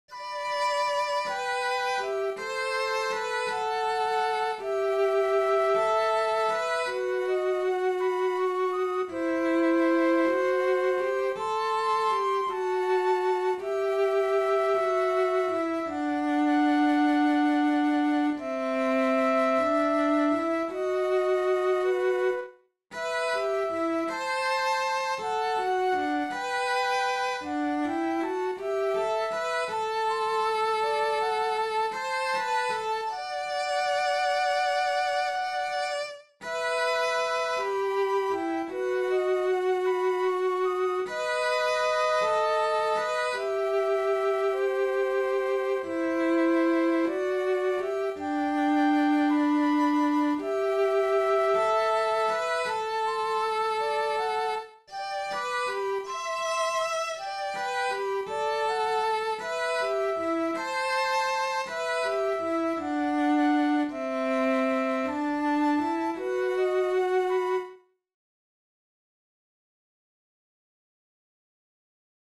Yopilvia-sello-ja-huilu.mp3